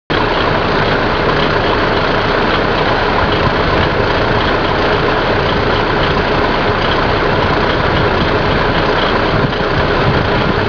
上写真は003、有田川町鉄道公園にて。
〜車両の音〜
・キハ58 アイドリング音
走っているのに乗れない以上、録れる音はこの程度です。
idoling.WAV